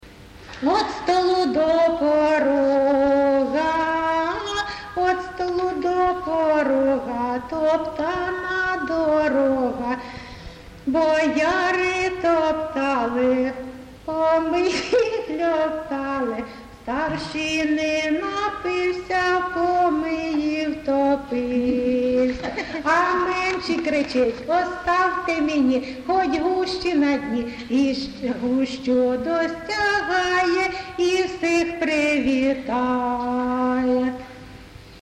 ЖанрВесільні
Місце записус-ще Зоря, Краматорський район, Донецька обл., Україна, Слобожанщина